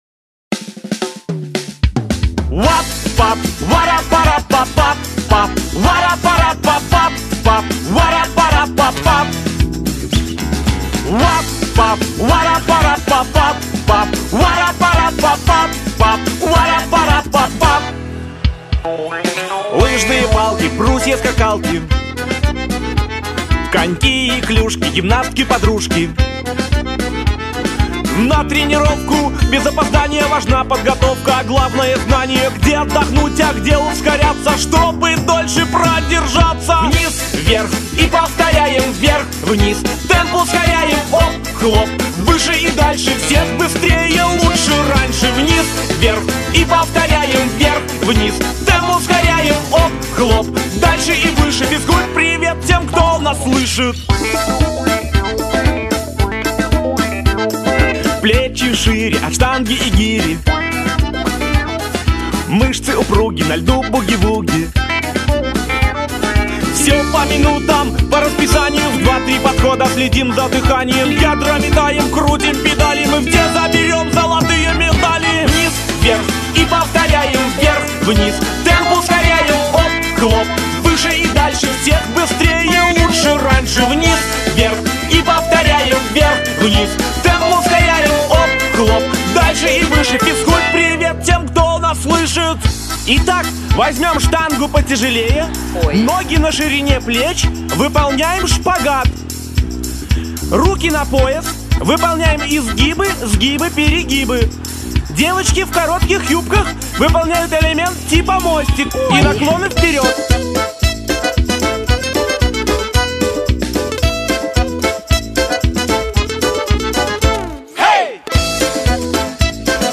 • Категория: Детские песни
теги: зарядка, детский сад, малышковые